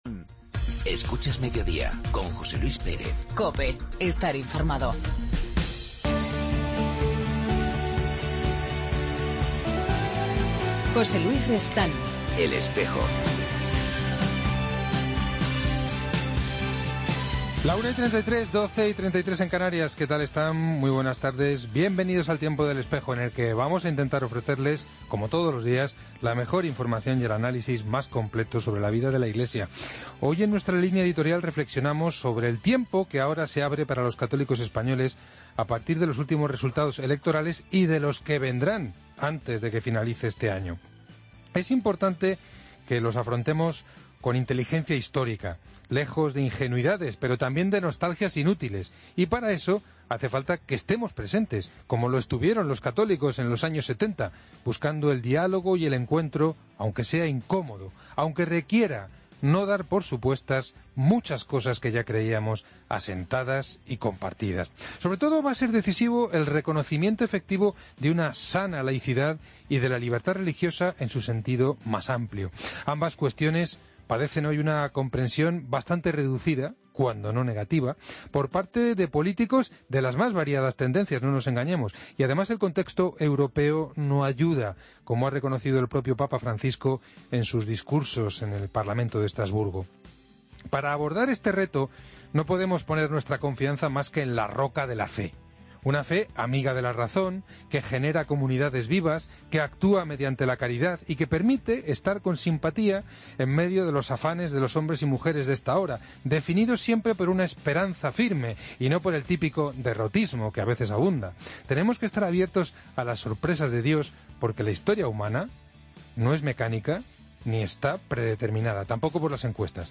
Hoy hablamos con el obispo auxiliar de la diócesis de Xai-Xai, en Mozambique, el mercedario español Alberto Vera Aréjula, que recientemente ha comenzado su misión episcopal en esta sede.